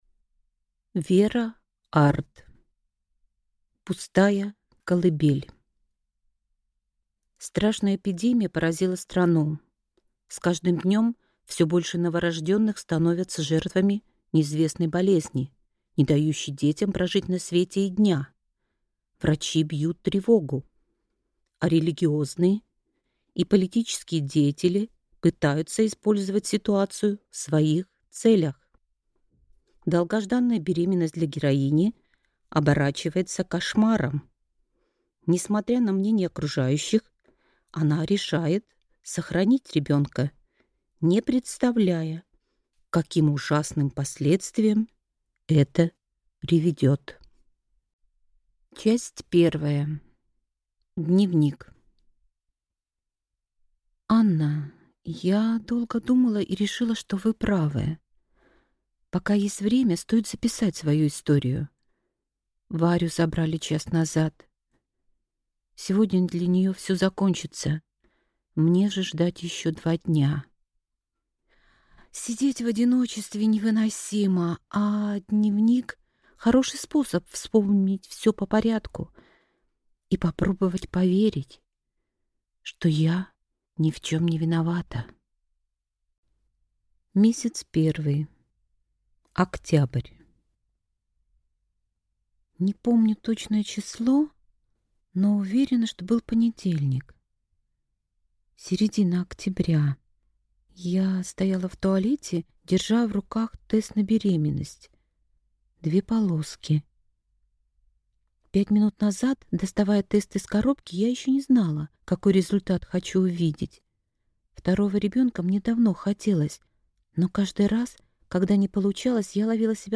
Аудиокнига Пустая колыбель | Библиотека аудиокниг
Прослушать и бесплатно скачать фрагмент аудиокниги